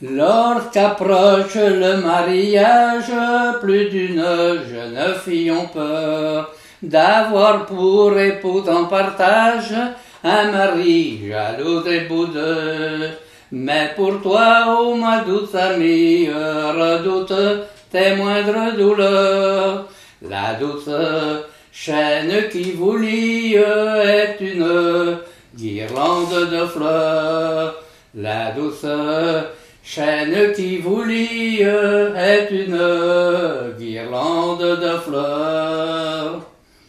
Mémoires et Patrimoines vivants - RaddO est une base de données d'archives iconographiques et sonores.
Chantée au repas de noce
Pièce musicale inédite